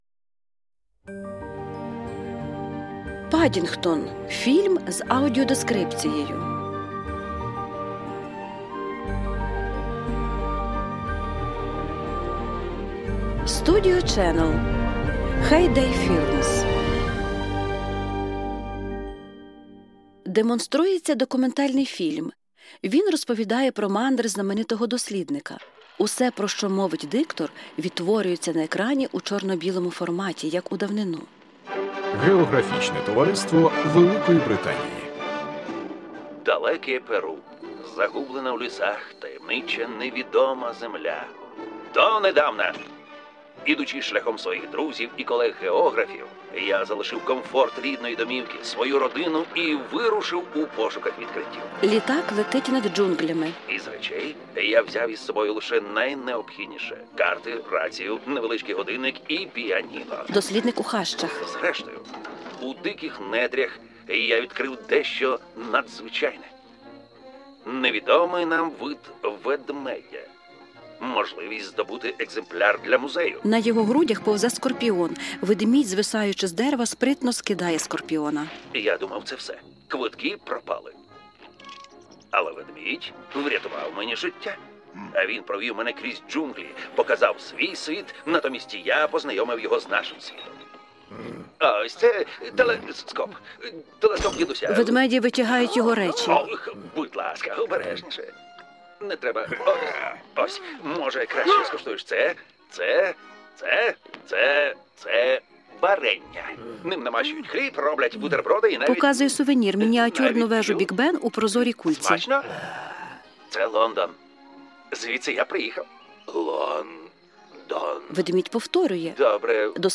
Пригоди Паддінгтона (Художній фільм з аудіодискрипцією).mp3